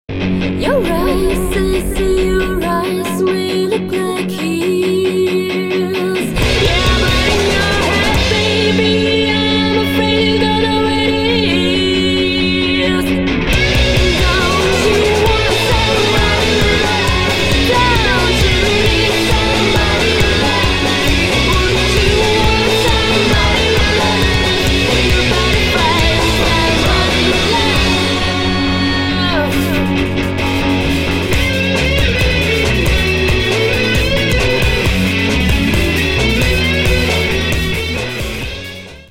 • Качество: 320, Stereo
красивые
женский вокал
психоделический рок
психоделика
60-е